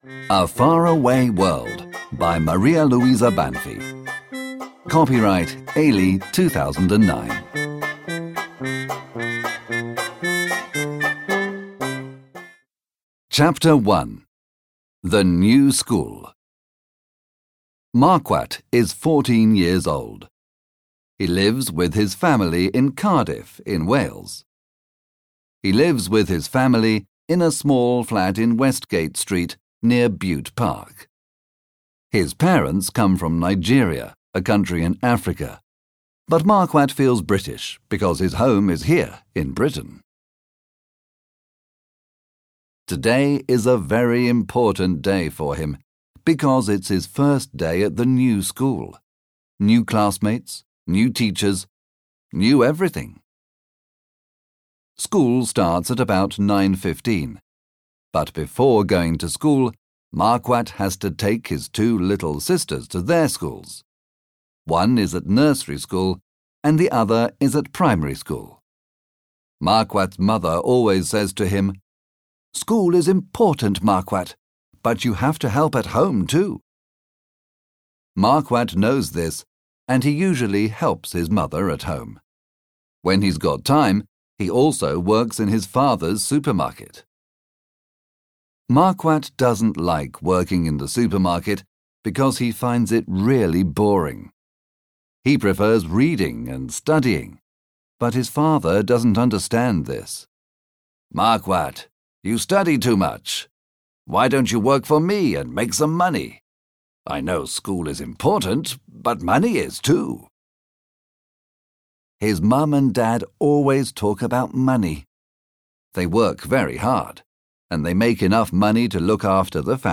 Obtížnost poslechu odpovídá jazykové úrovni A2 podle Společného evropského referenčního rámce, tj. pro studenty angličtiny na úrovni mírně pokročilých začátečníků.
AudioKniha ke stažení, 6 x mp3, délka 23 min., velikost 30,9 MB, česky